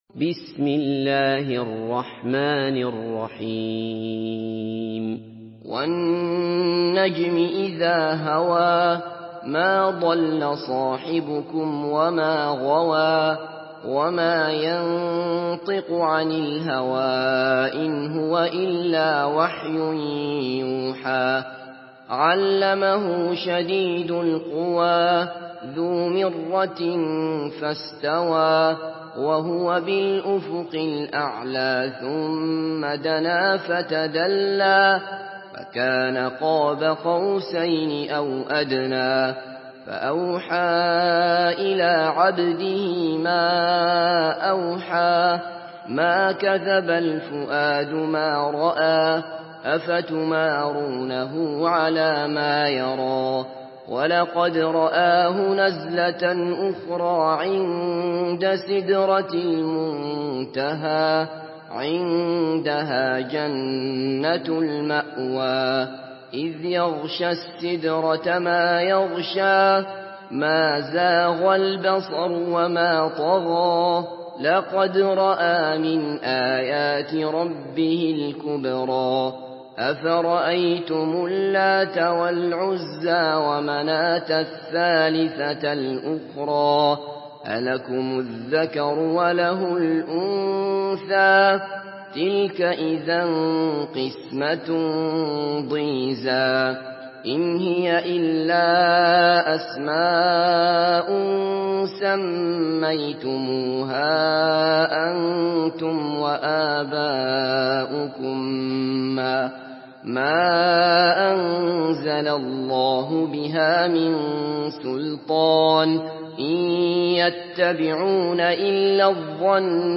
Surah An-Najm MP3 by Abdullah Basfar in Hafs An Asim narration.
Murattal Hafs An Asim